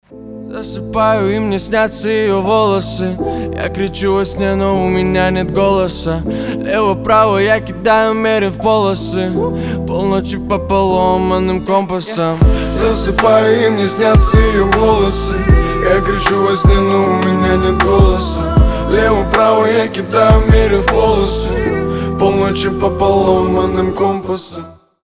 рэп
дуэт